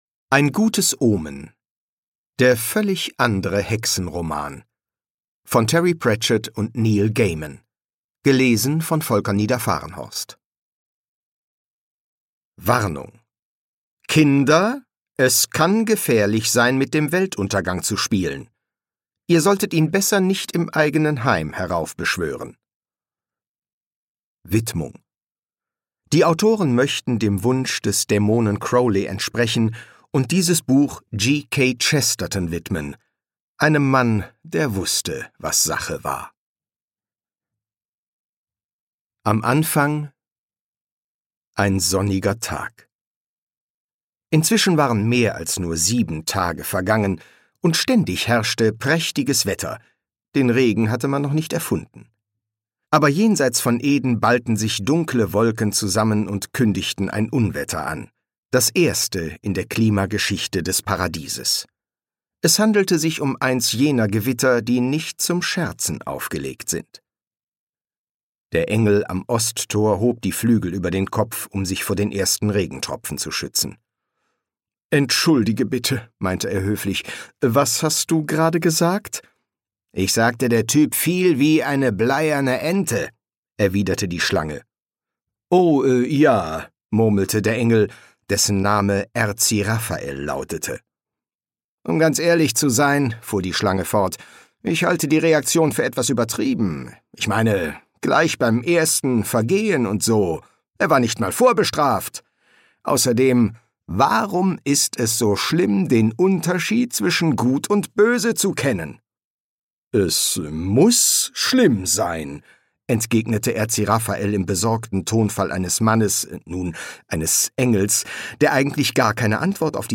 Hörbuch Ein gutes Omen von Terry Pratchett und Neil Gaiman.
Ukázka z knihy